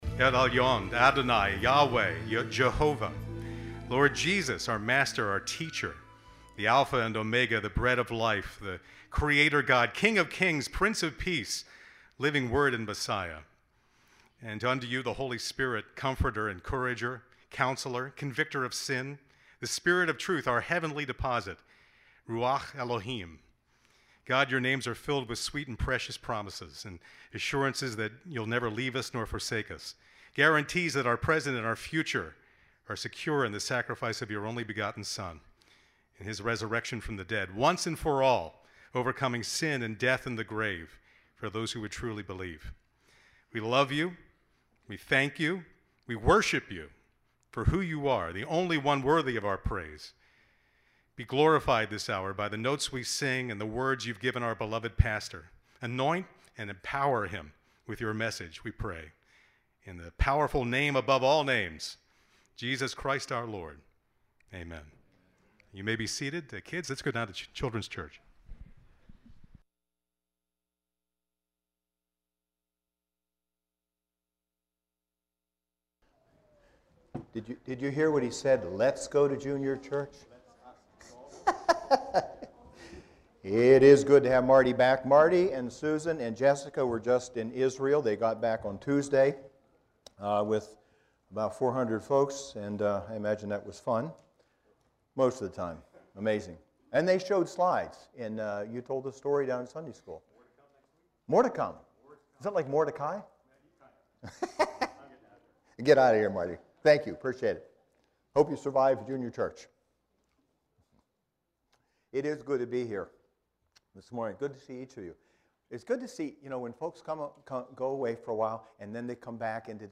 SERMONS - Grace Fellowship Church